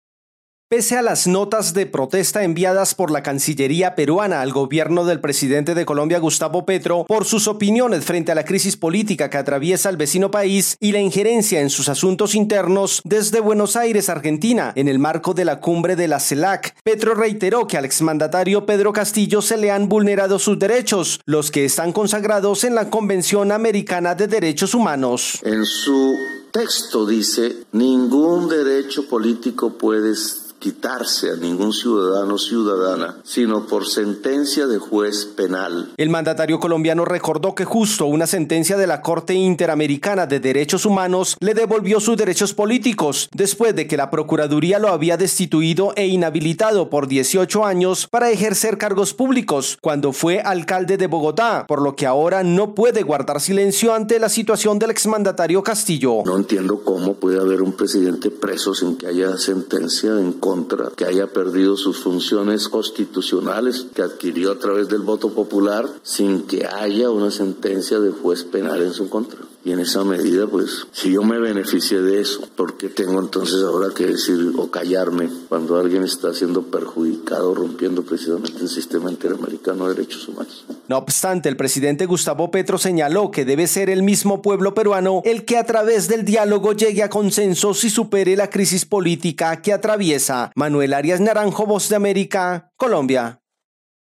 El presidente de Colombia, Gustavo Petro, reiteró su defensa al expresidente de Perú, Pedro Castillo, e insistió en que al exmandatario se le están violando sus derechos políticos. Desde Colombia informa el corresponsal de la Voz de América